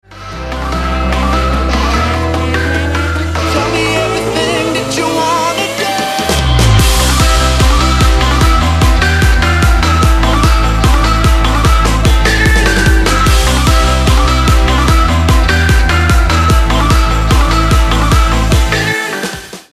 • Качество: 128, Stereo
progressive house
Стиль: Progressive house